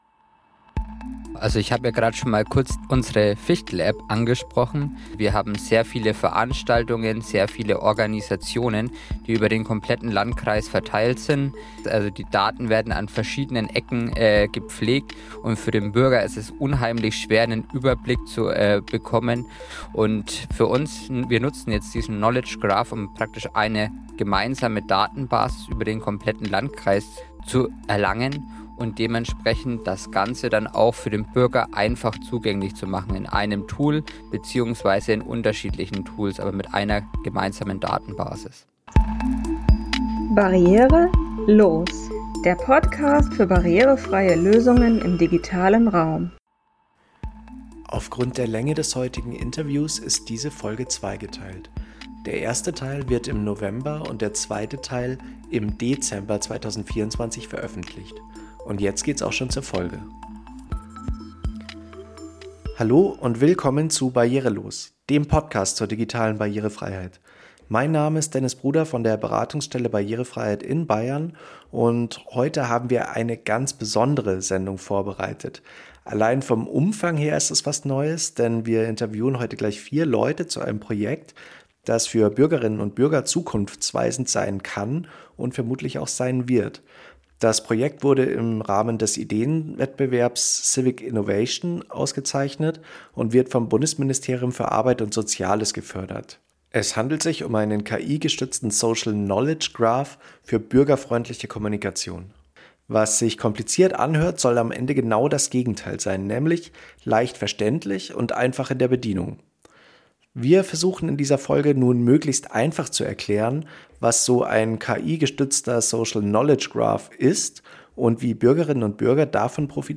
Beschreibung vor 1 Jahr In dieser Folge des Podcasts "Barriere los!" sprechen wir mit vier Projektpartnerinnen und -partnern, die gemeinsam am Modellprojekt „Smartes Fichtelgebirge“ arbeiten. Im Zentrum steht ein KI-gestützter Social Knowledge Graph, der verschiedene innovative Ansätze miteinander vereint.
Erfahren Sie im Interview, was dieses zukunftsweisende Projekt so besonders macht und wie daraus ein Produkt entsteht, das verständlich, leicht auffindbar und intuitiv zu bedienen ist.